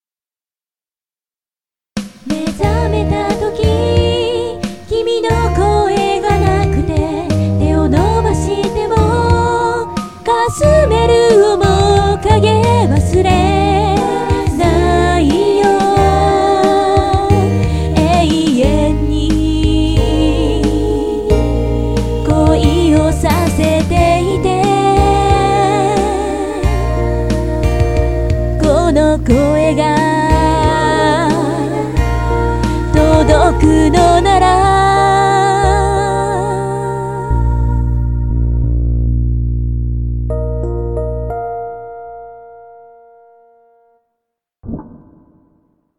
全体にビブラートのかかった独得の声質で、大人っぽい歌声です。